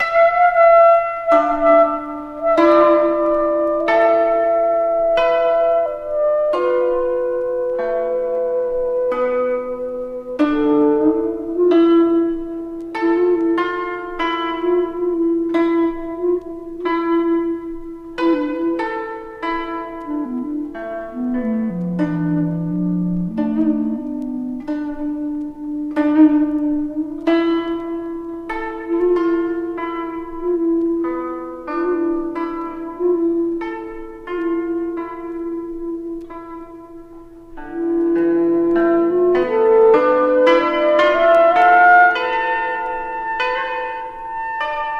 三者三様の日本的な美しい音色と響きがどこかへ誘ってくれる。
Jazz, New Age, Abstract　Germany　12inchレコード　33rpm　Stereo